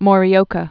(môrē-ōkə)